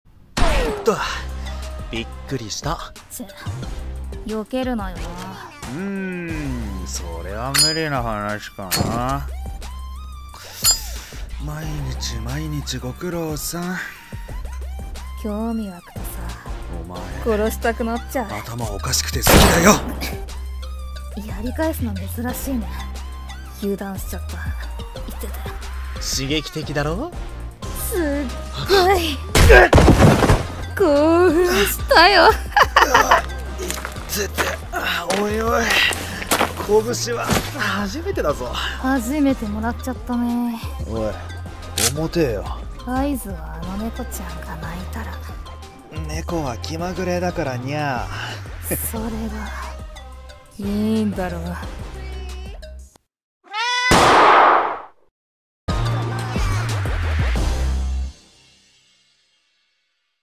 【銃 掛け合い 2人声劇】